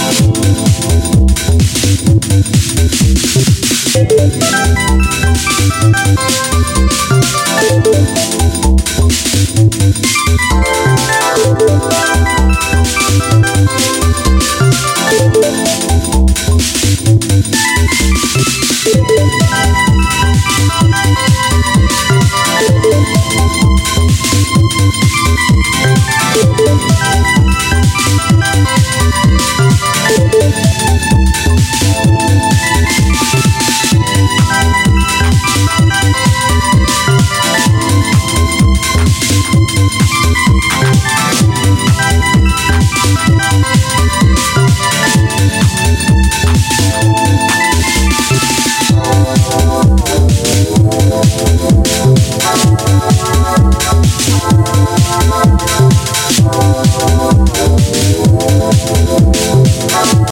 Italian underground deep house classic